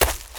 High Quality Footsteps
STEPS Leaves, Run 15.wav